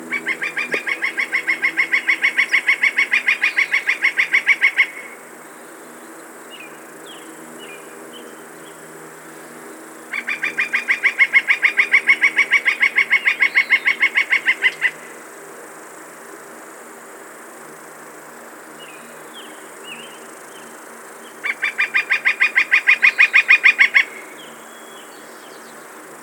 1. Northern Flicker (Colaptes auratus)
A loud, ringing “kleer kleer kleer” or a steady, repetitive rattle.